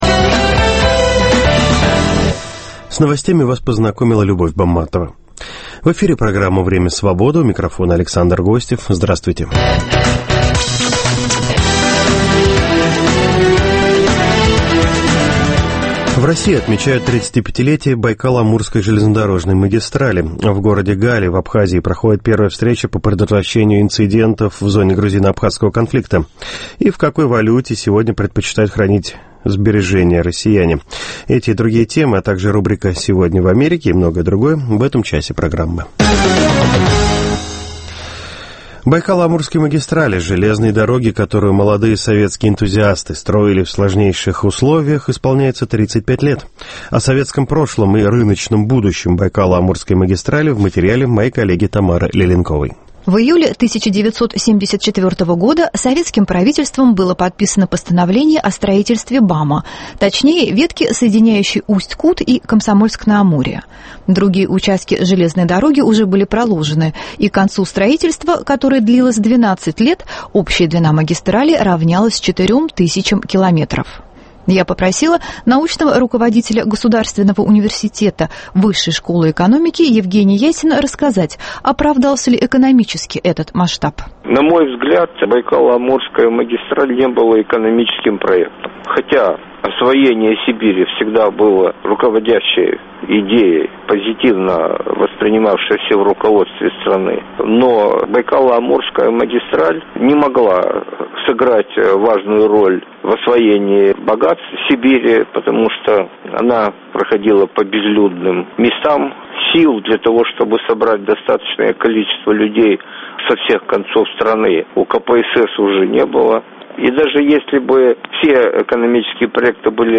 35 лет Байкало-Амурской магистрали Интервью с министром обороны Литвы Расой Юкнявичене